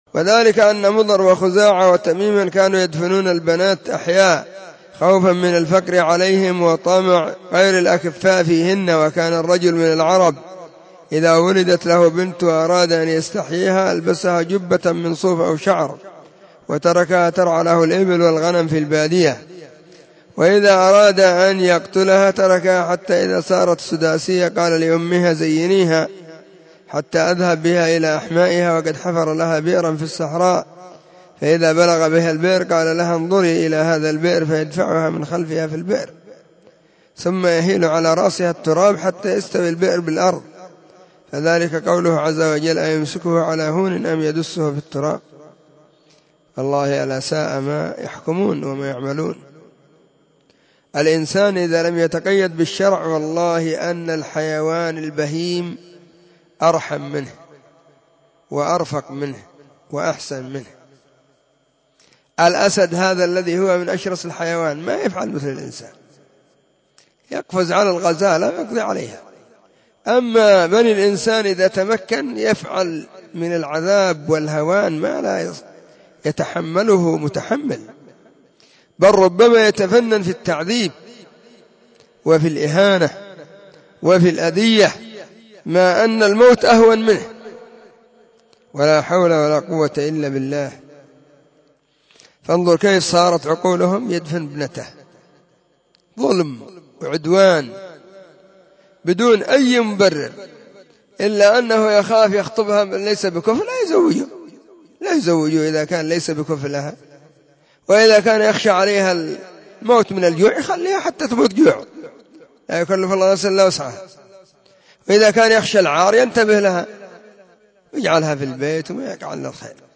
💢نصيحة قيمة بعنوان💢 بيان حكم الإجهاض *
📢 مسجد الصحابة بالغيضة, المهرة، اليمن حرسها الله.